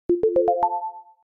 bubble-notification.mp3